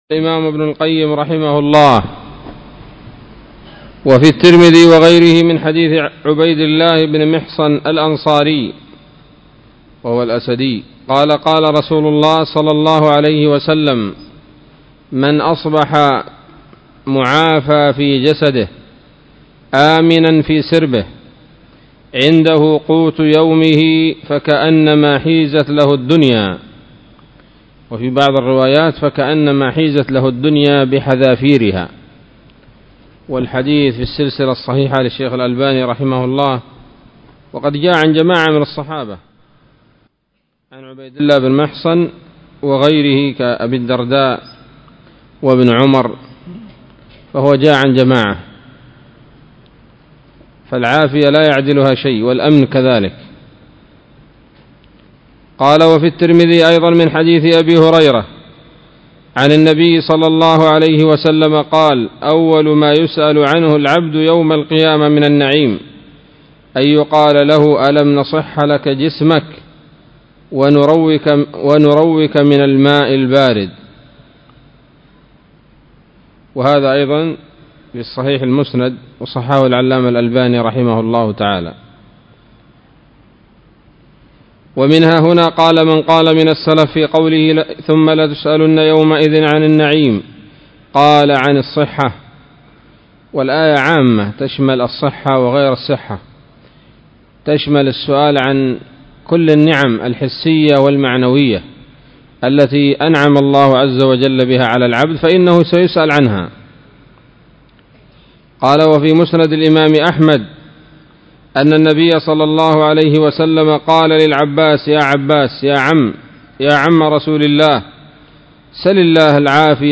الدرس التاسع والخمسون من كتاب الطب النبوي لابن القيم